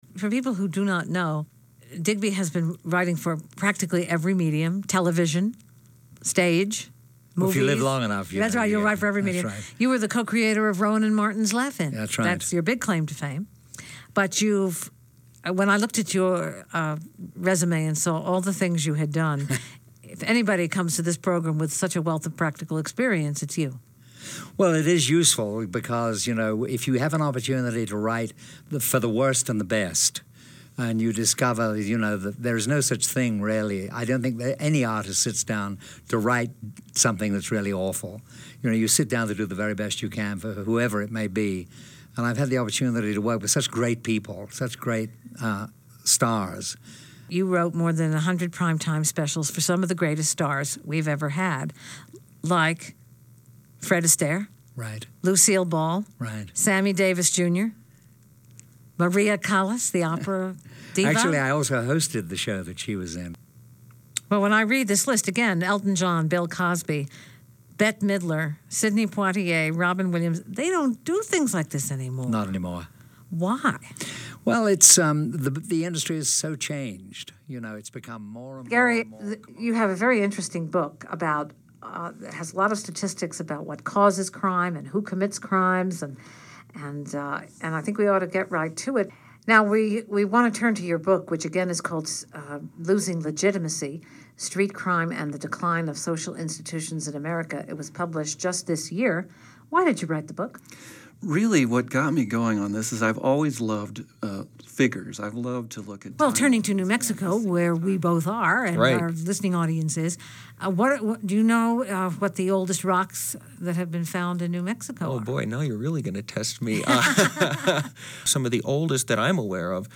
interviews literary and musical luminaries